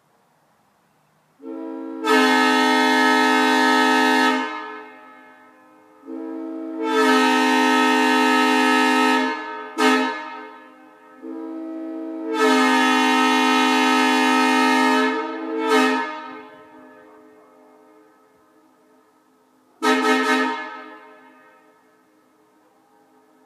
This is rare variation of the M3. It was developed for use on Deleware & Hudson RS3s with the 2 and 4 bells facing forward and the tilted 1 bell facing backward at an angle to clear the cab roof.